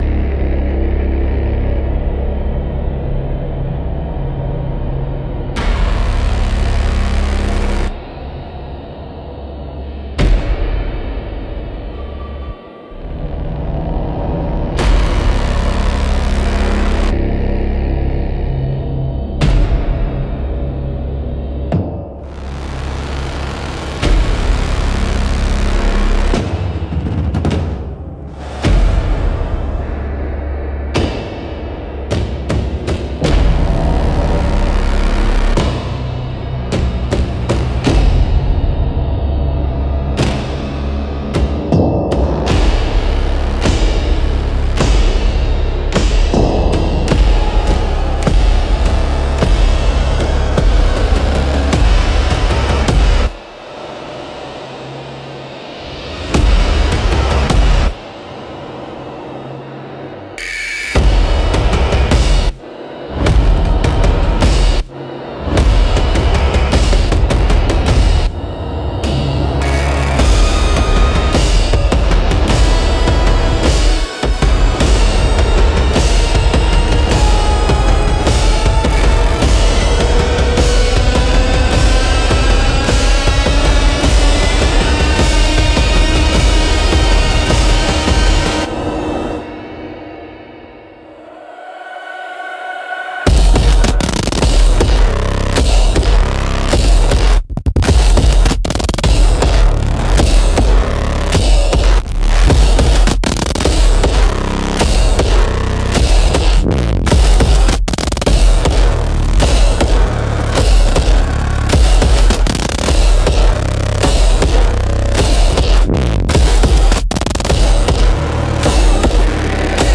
Instrumental - Cry of the Digital Forest - 2.44